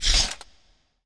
tank_skill_shieldbuff_end.wav